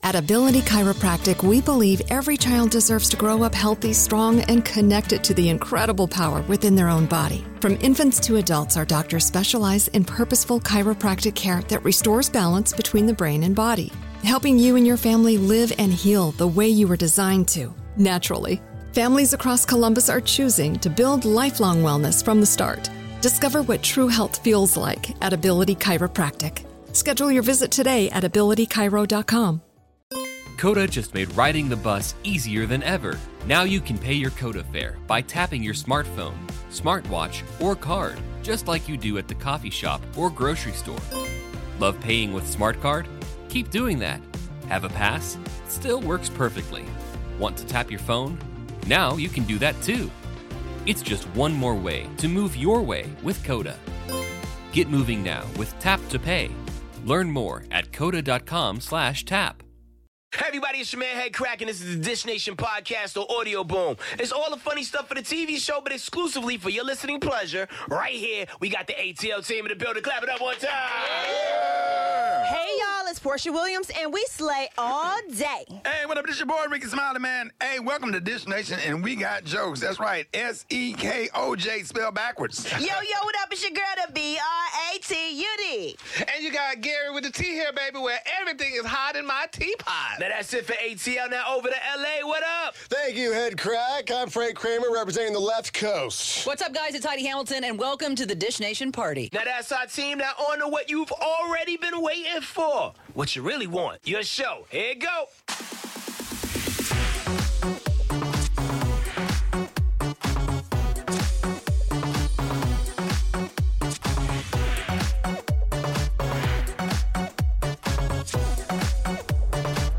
Guest host Torrei Hart is back and exclusive news about Gwyneth Paltrow's new venture. Plus all the latest on Eva Longoria, Amy Schumer, Dax Shepard, Kristen Bell, Kendall Jenner and much more!